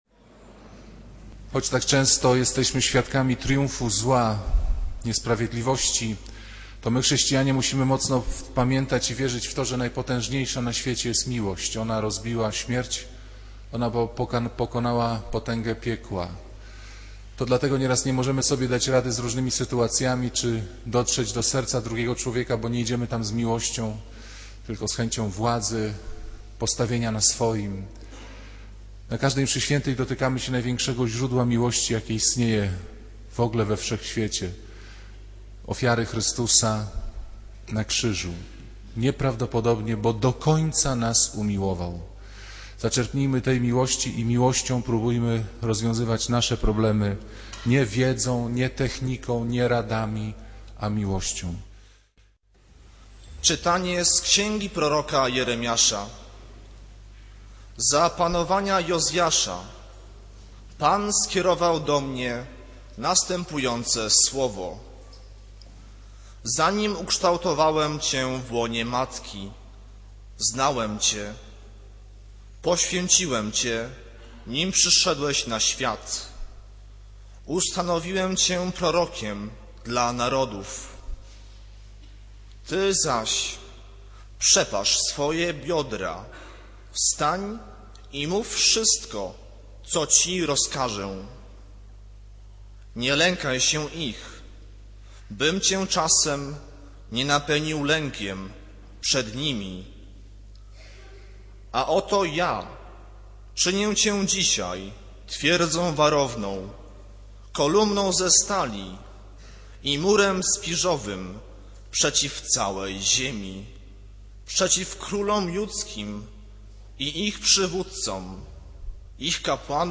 Kazanie z 31 stycznia 2010 r.